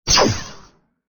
Shoot2.mp3